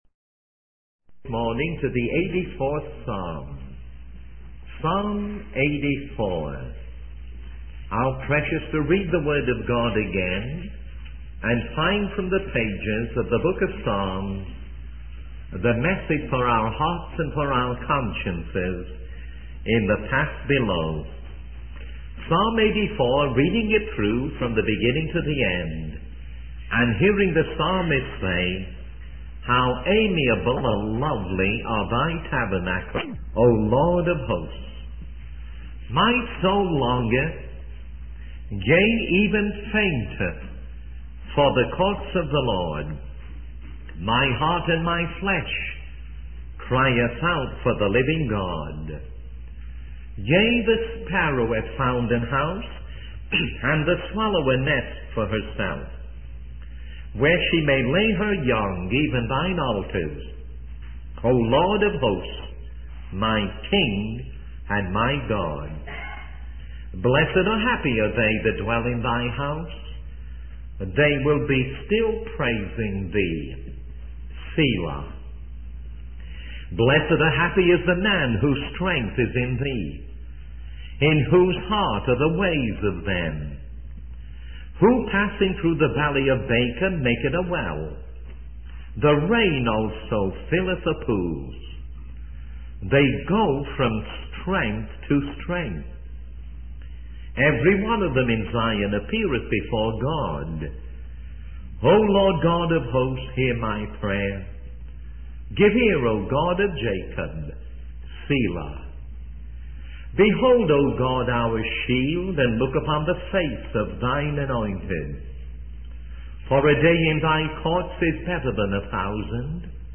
In this sermon, the speaker focuses on Psalm 84 and divides it into three sections: finding God, following God, and fellowshipping with God. The first section emphasizes the importance of recognizing that we cannot follow God in our own strength, but rather our strength comes from Him.